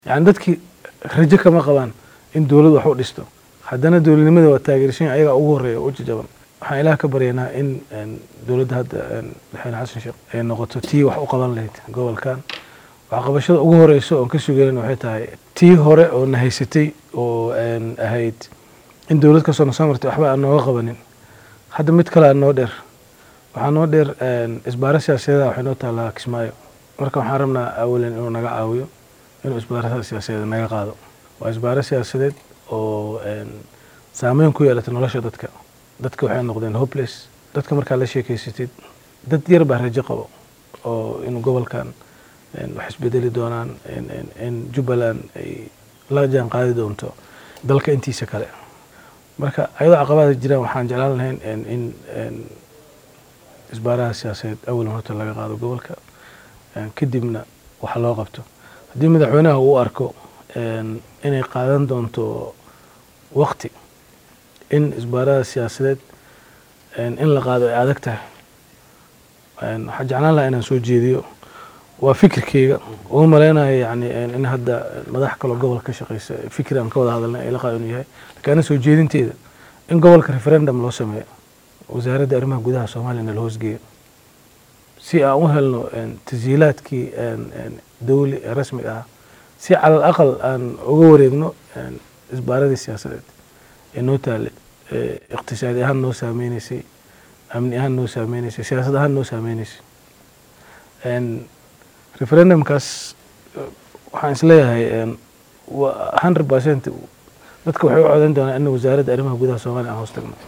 Guddoomiyaha Degmada Beledxaawo Cabdirashiid Cabdi Caroog oo saxaafadda la hadlay ayaa arrimo dhowr ah oo ay ka mid tahay xaaladda siyaasadeed ee Jubbaland soo hadal qaaday. Waxaa uu sheegay inuu doonayo in madaxweynaha cusub ee Soomaaliya Xasan Sheekh Maxamuud uu ka qaado isbaarada siyaasadeed. Guddoomiyaha ayaa xusay in shacabka degmadaasi ay maamulka Jubbaland ka yihiin waxa uu ugu yeeray muwaadiniinta heerka labaad.